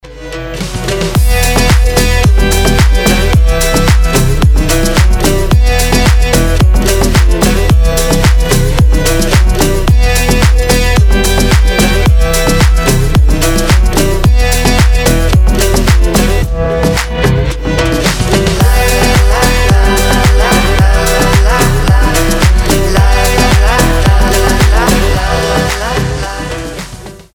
• Качество: 320, Stereo
гитара
громкие
deep house
восточные